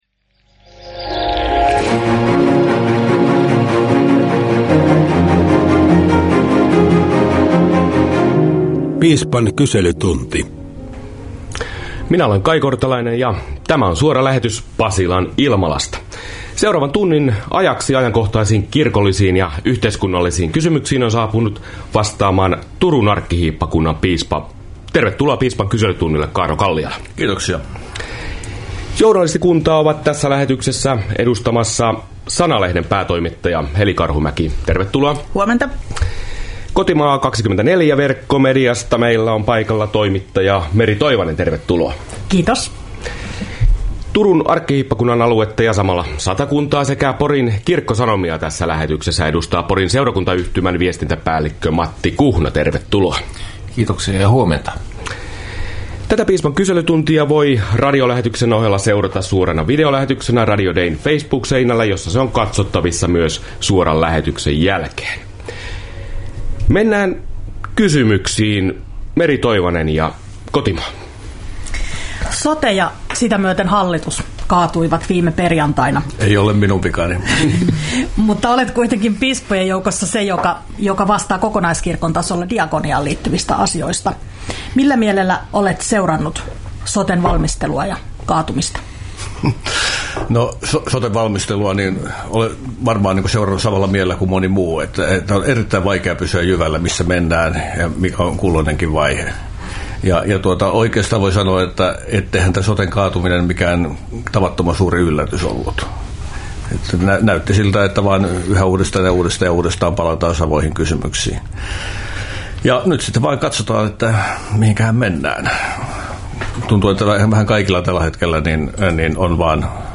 Turun arkkihiippakunnan piispa Kaarlo Kalliala avasi Radio Dein suorassa Piispan kyselytunti -lähetyksessä tavoitteita, jotka Suomen evankelis-luterilaisen kirkko pyrkii saamaan myös tulevasta hallitusohjelmasta neuvottelevien puolueiden tavoitteiksi.